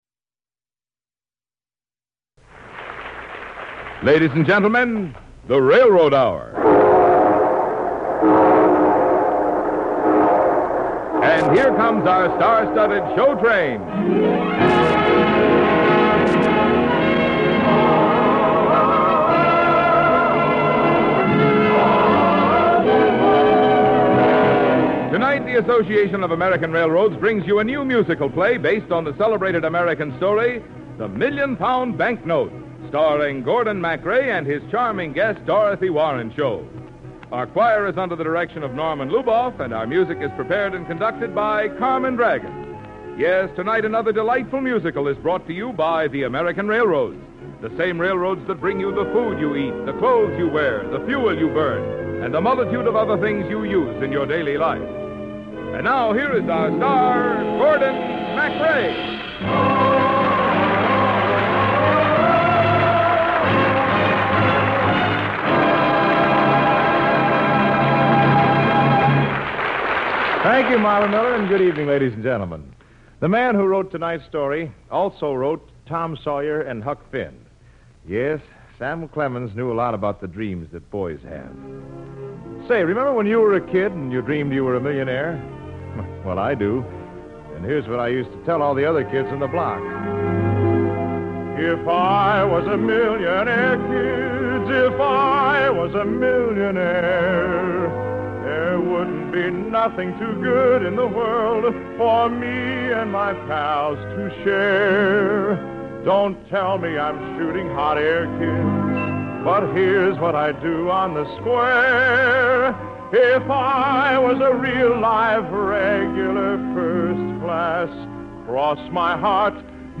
The Railroad Hour was a delightful radio series that aired musical dramas and comedies from the late 1940s to the mid-1950s. Sponsored by the Association of American Railroads, the show condensed beloved musicals and operettas into shorter formats, focusing on works written before 1943.